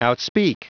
Prononciation du mot outspeak en anglais (fichier audio)